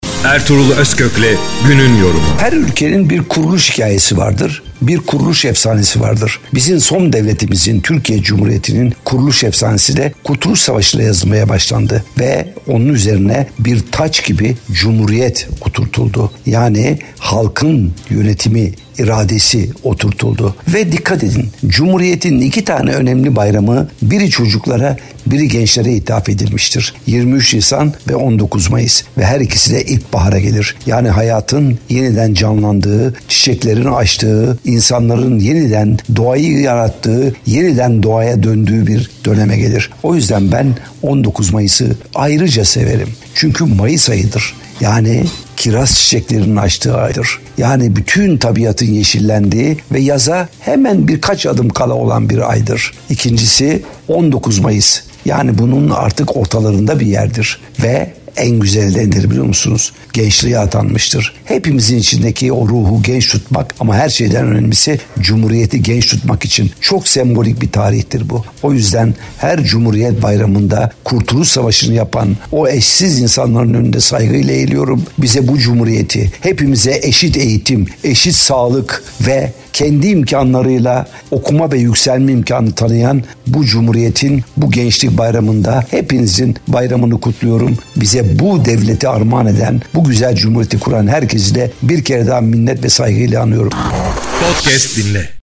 ERTUGRUL-OZKOK_19-MAYIS-YORUM-JINGLELI.mp3